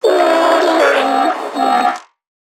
NPC_Creatures_Vocalisations_Infected [115].wav